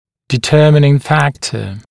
[dɪ’tɜːmɪnɪŋ ‘fæktə][ди’тё:минин ‘фэктэ]определяющий фактор